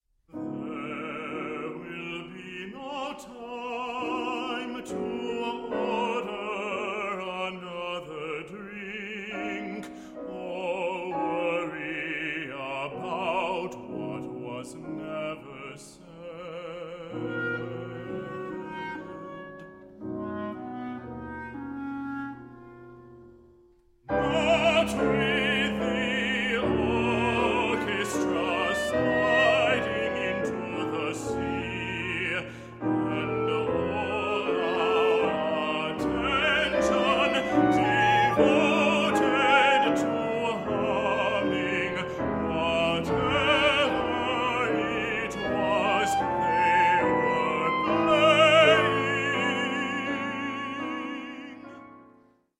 24 bit digital recording
baritone
clarinet
piano